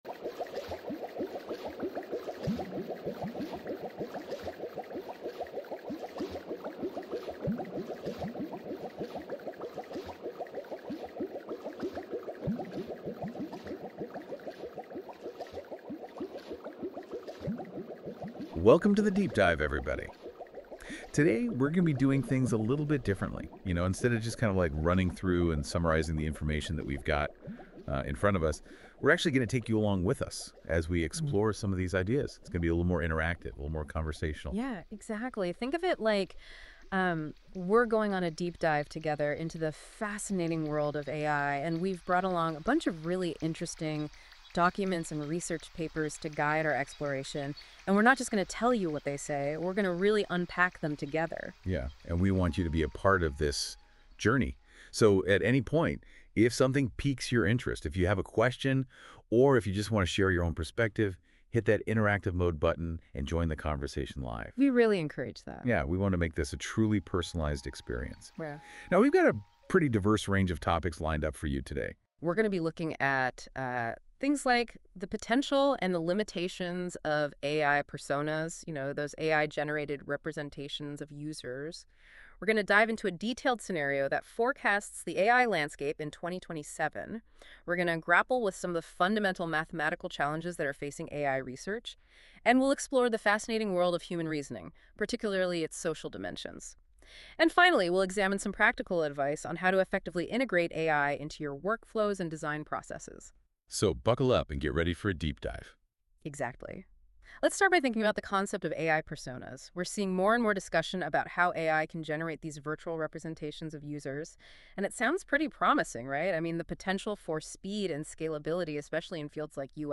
Duration: 31:59minAudience: AI and UX design future skills Visit the Interactive mode Audio Overview: NotebookLM (with your Google account)